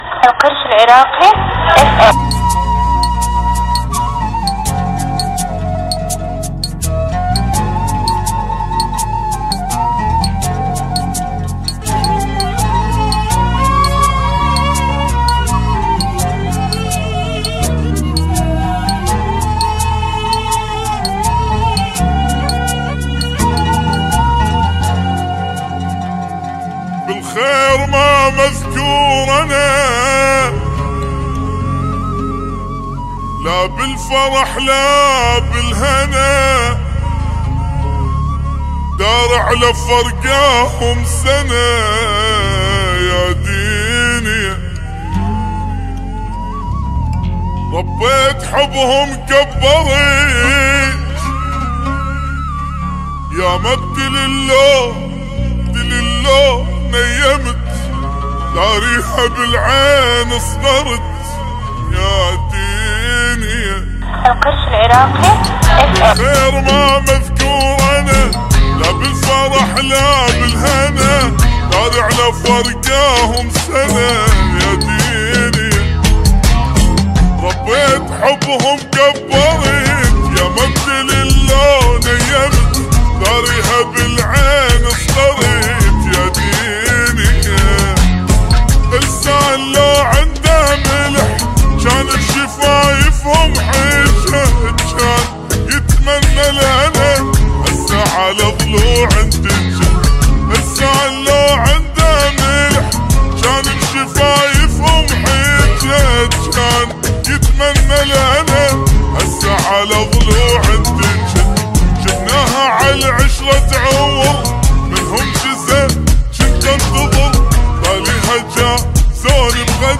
اغاني عراقيه حزينه
بطئ